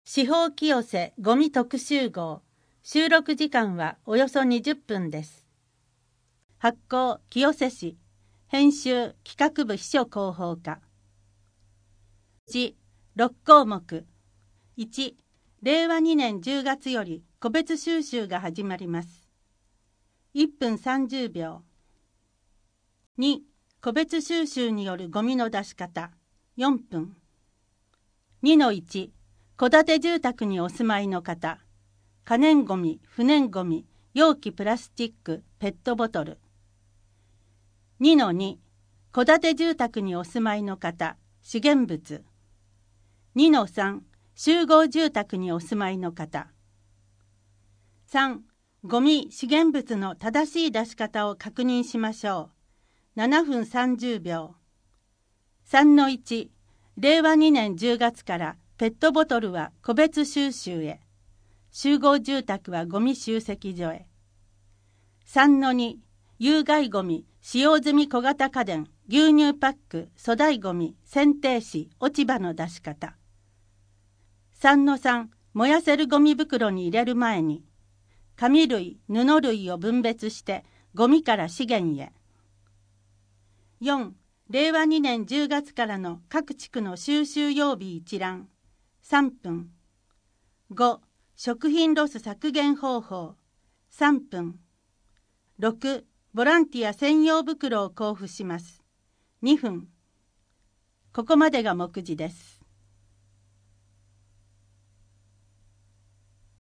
「意識と工夫が重要です」 ボランティア専用袋を交付します 声の広報 声の広報は清瀬市公共刊行物音訳機関が制作しています。